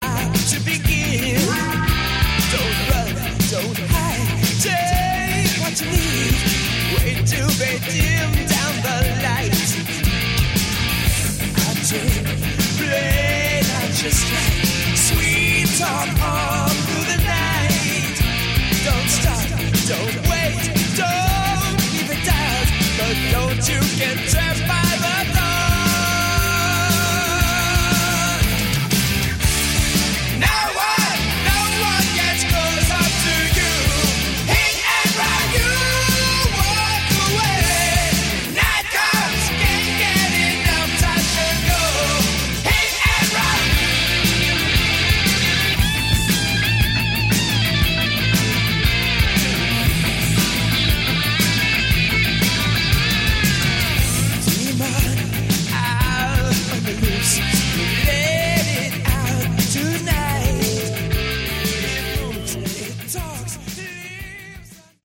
Category: Hard Rock
lead and backing vocals
keyboards
guitar
drums